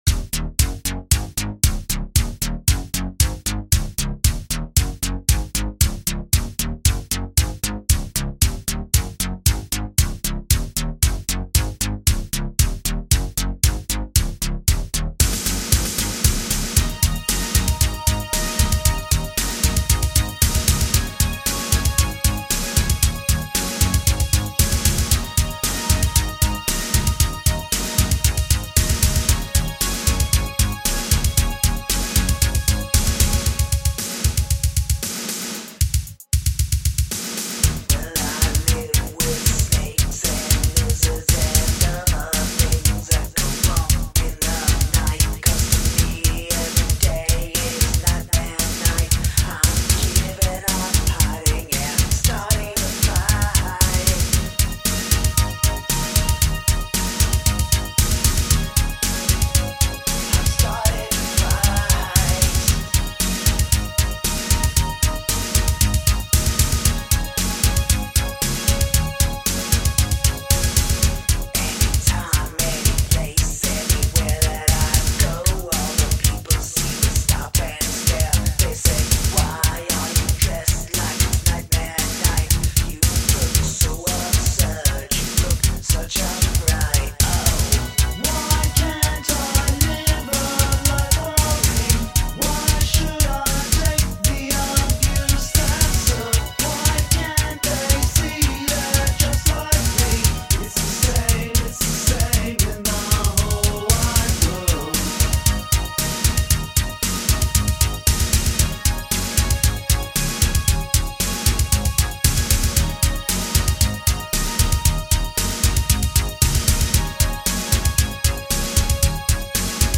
A cover